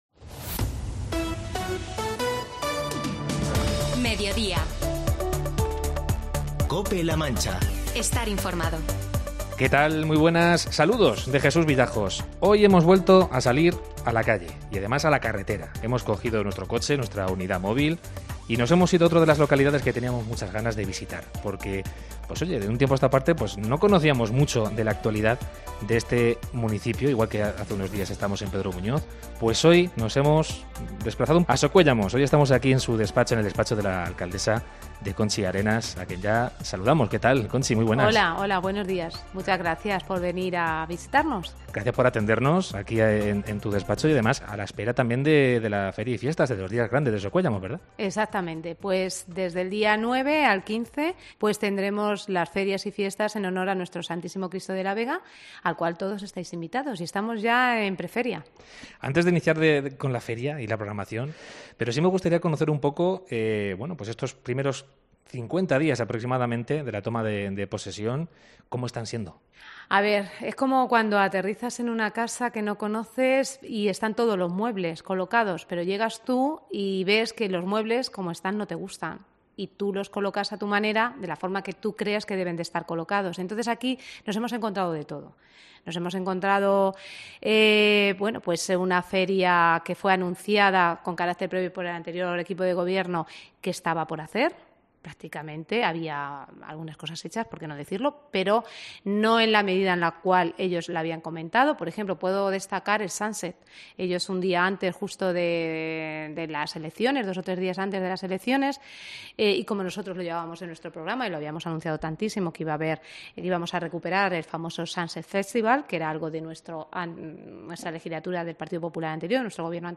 Entrevista a Conchi Arenas, alcaldesa de Socuéllamos